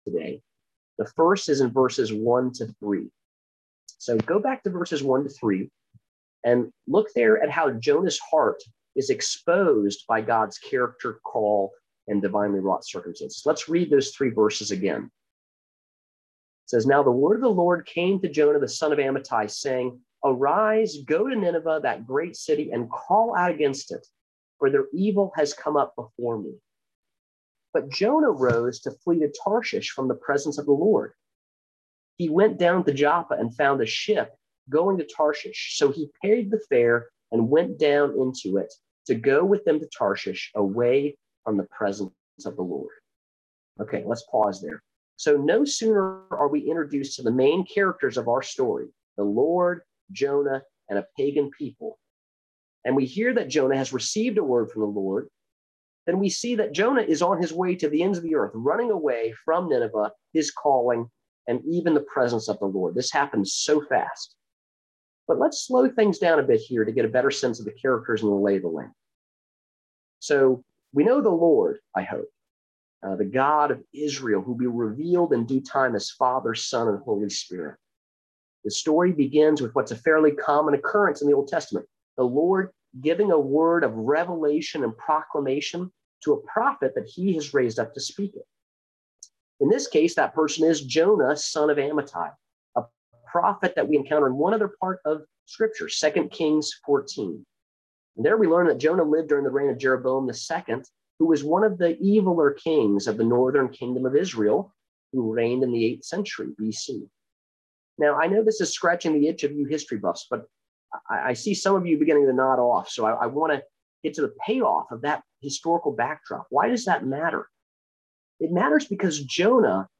2020 Church Sermons on Audio | ccmcenglish